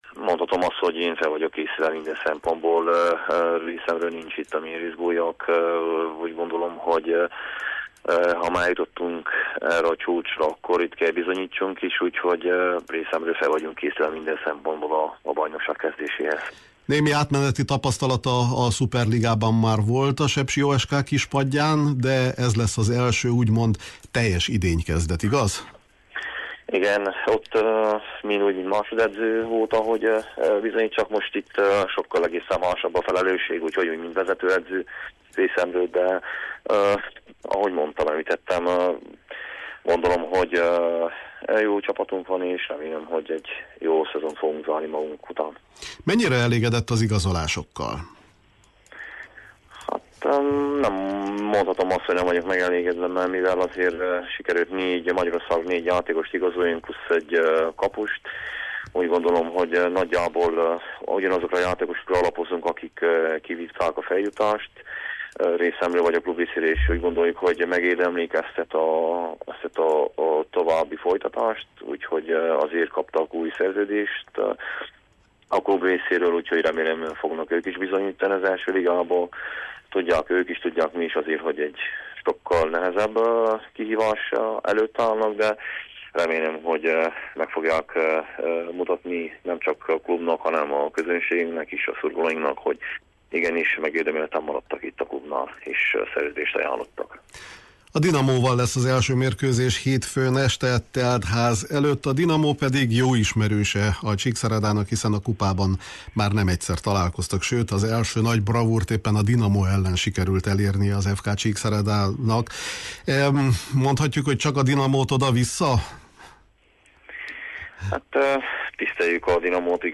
A Kispad műsorunkban készült interjúban az is kiderül, mi lehet az FK erénye a „nagyok” között.